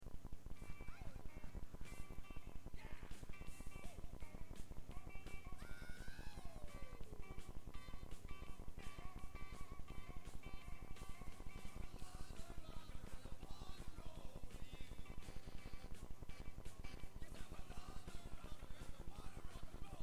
Sen var det en massa flams om trasiga hörlurar, glappande sladdar och annat tekniskt otyg som ställde till nerverna för oss. En hel del musik hann vi med iallafall....